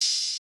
{OpenHat} first take.wav